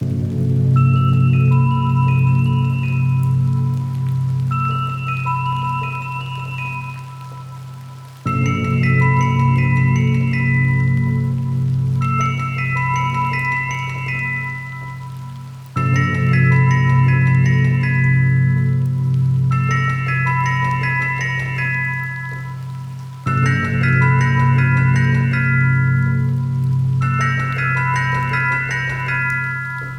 Loop